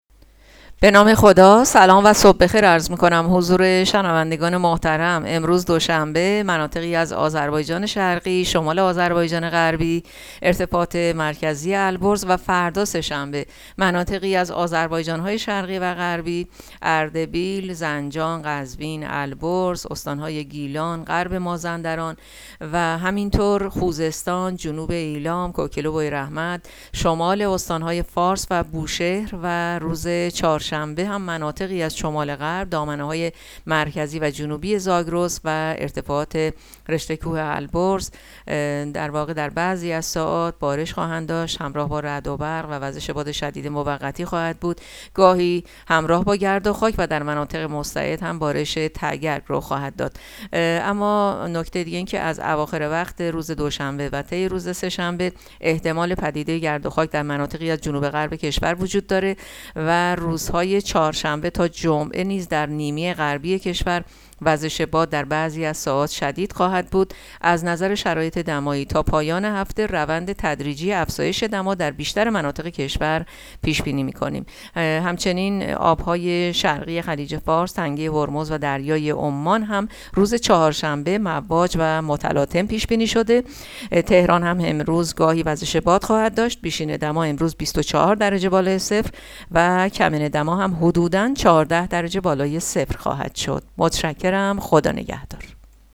گزارش رادیو اینترنتی پایگاه‌ خبری از آخرین وضعیت آب‌وهوای یکم اردیبهشت؛